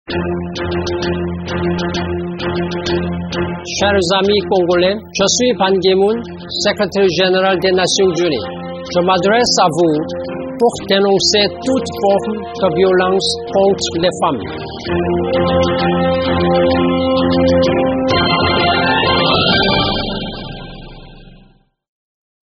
Ecoutez ici les messages de Ban Ki-moon, secréteur général de l’ONU, à l’occasion de la campagne de lutte contre les violences faites aux femmes et aux enfants: